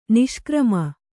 ♪ niṣkrama